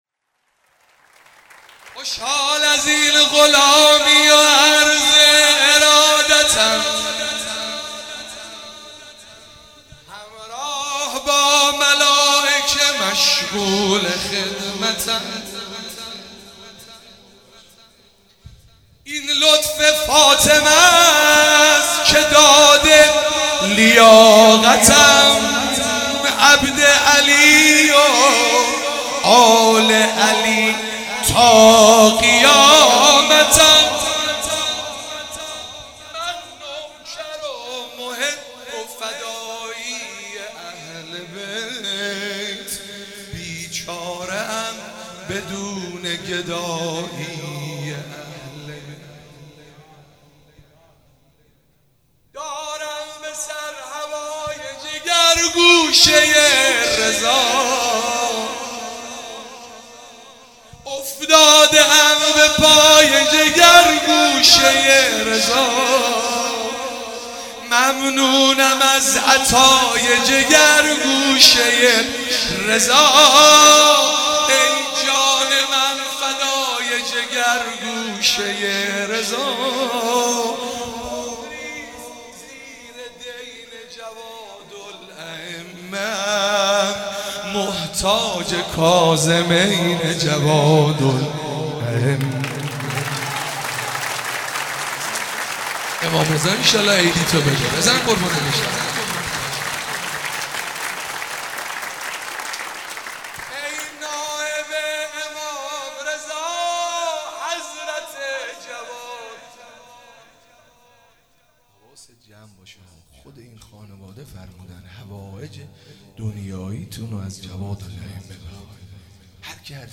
چهاراه شهید شیرودی حسینیه حضرت زینب (سلام الله علیها)
شور- از آسمون مهتاب اومده